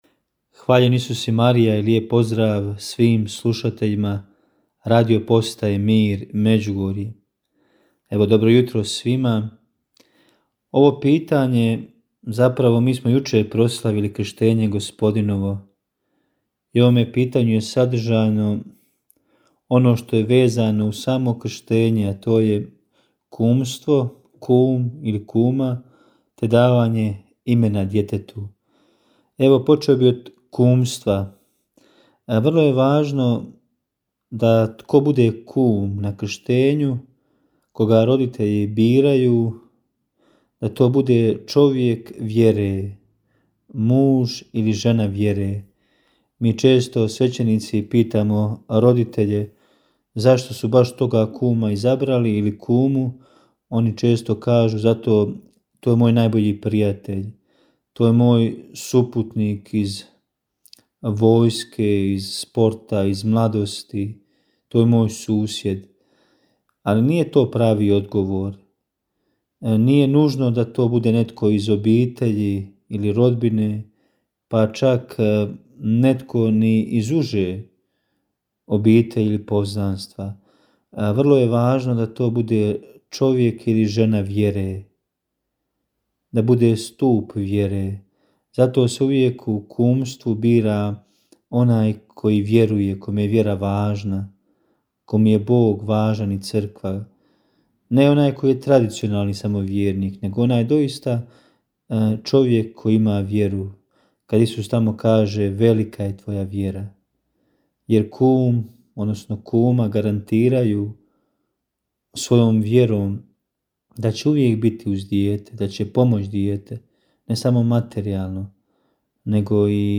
U njoj na pitanja slušatelja odgovaraju svećenici, suradnici Radiopostaje Mir Međugorje.